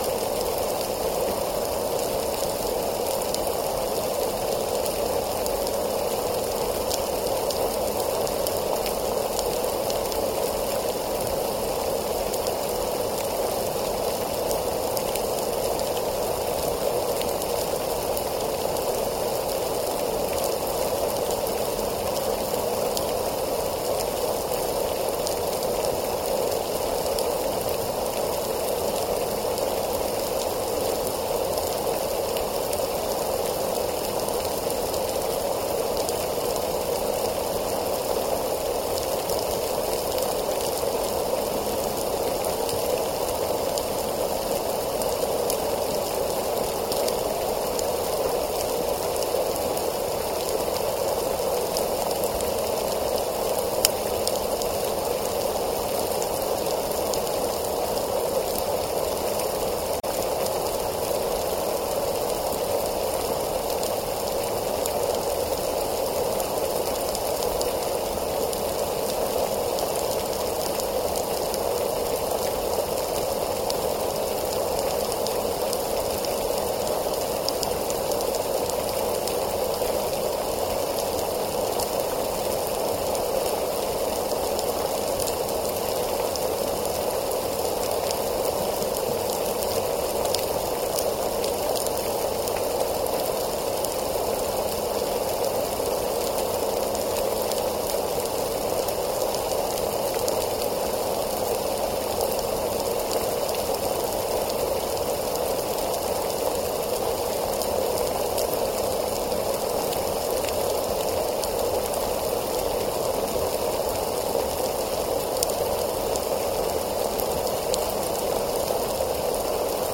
Demonstration soundscapes
biophony